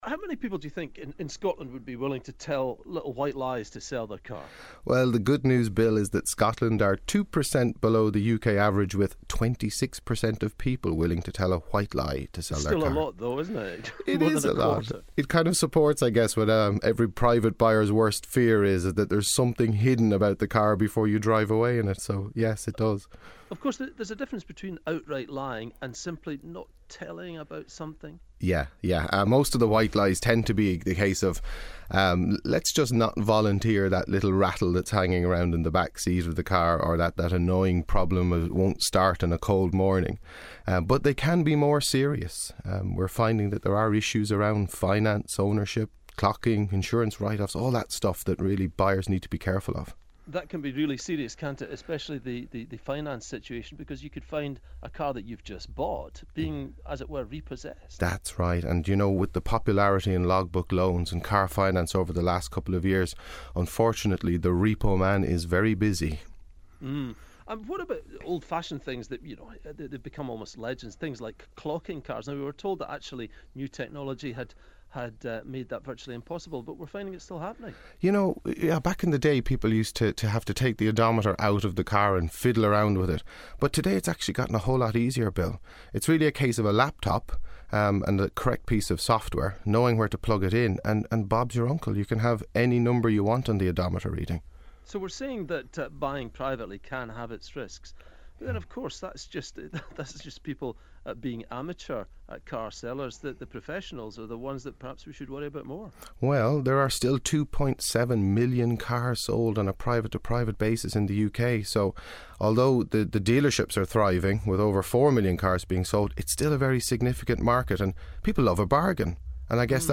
Interview with BBC Radio Scotland